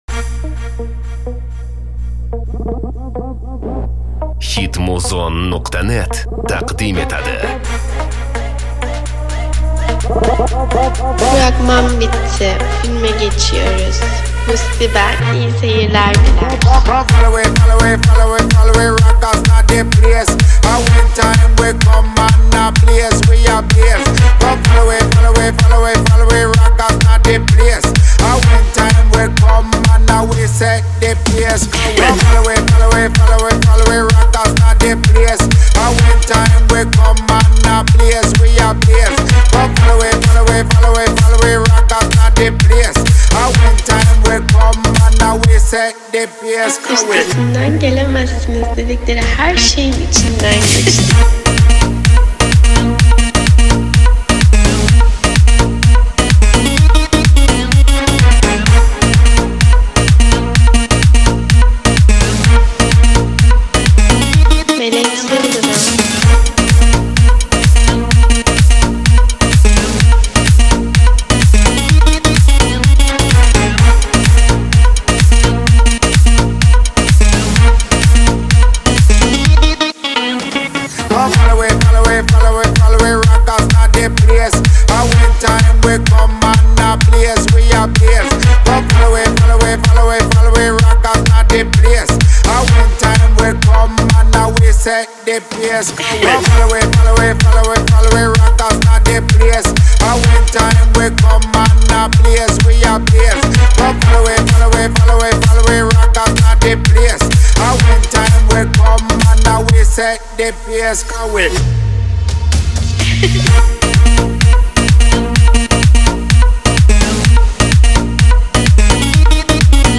Bass music, Электронная музыка в машину, Клубная музыка бас